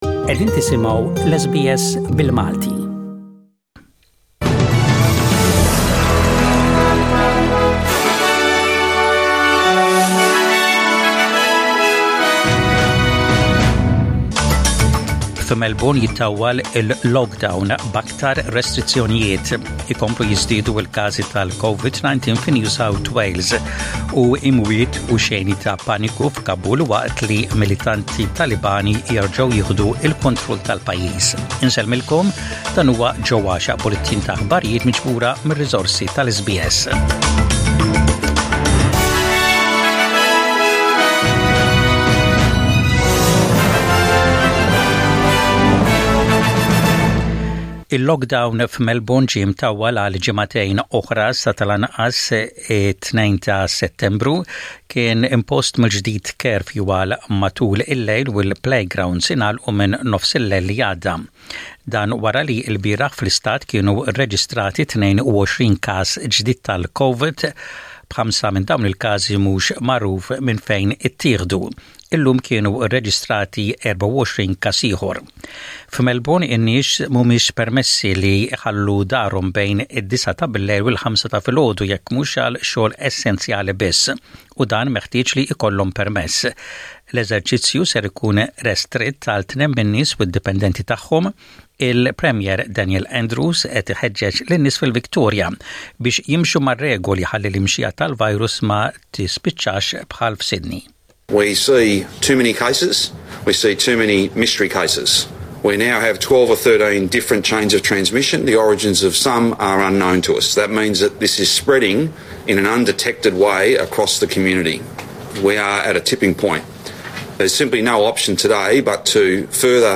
SBS Radio | Aħbarijiet bil-Malti: 17/08/21